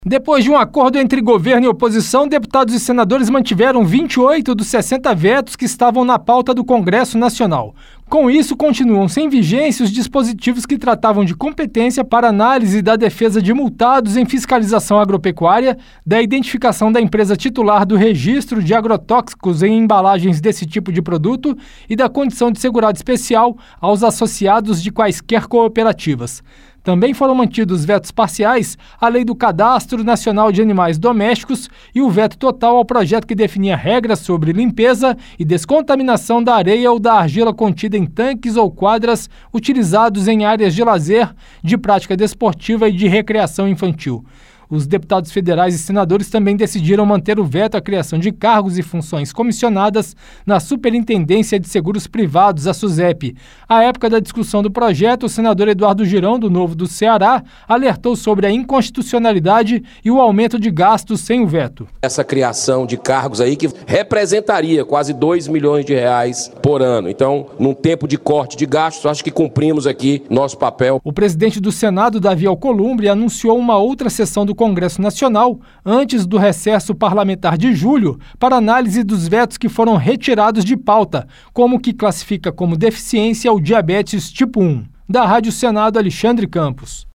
Senador Eduardo Girão